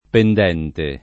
vai all'elenco alfabetico delle voci ingrandisci il carattere 100% rimpicciolisci il carattere stampa invia tramite posta elettronica codividi su Facebook pendente [ pend $ nte ] part. pres. di pendere , agg., s. m.